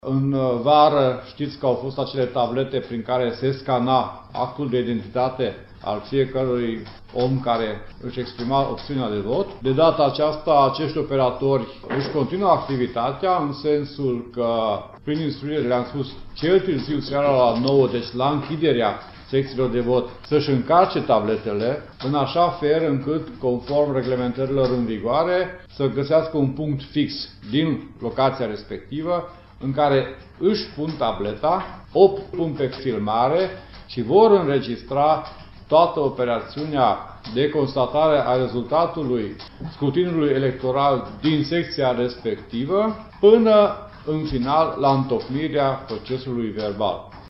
Tabletele folosite la alegerile locale pentru scanarea actului de identitate vor filma şi înregistra întreg procesul de numărare a voturilor în fiecare secţie, pentru a preveni eventuale erori. Subprefectul de Timiş  Zoltan Marossy spune că operatorii vor trebui să încarce tabletele seara după care să găsească un punct fix din care să înregistreze procesul de numărare a voturilor.